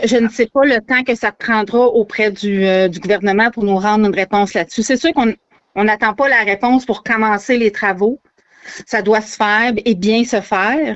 En entrevue, Mme St-Amour a mentionné que les travaux pourraient bientôt commencer, malgré qu’ils attendent de connaître la somme que versera Québec.